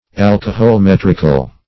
Alcoholmetrical \Al`co*hol*met"ric*al\
alcoholmetrical.mp3